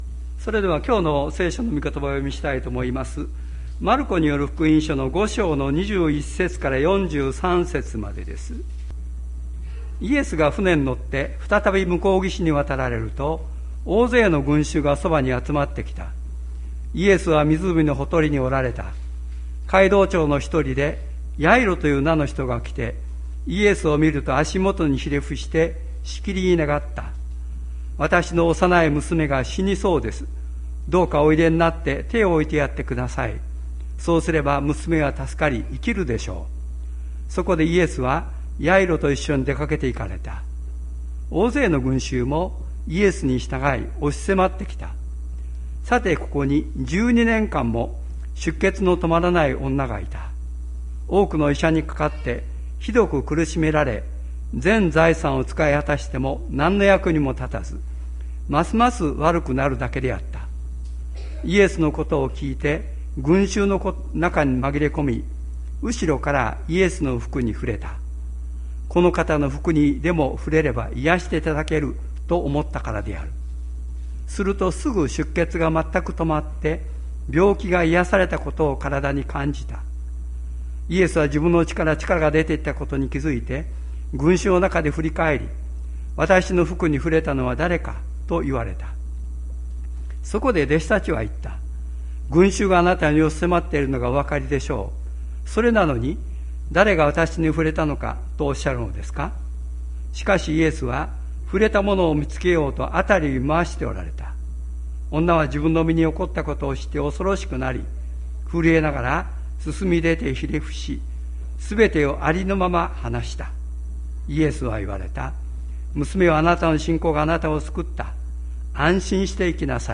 2022年08月21日朝の礼拝「死せる者、起きなさい」吹田市千里山のキリスト教会
千里山教会 2022年08月21日の礼拝メッセージ。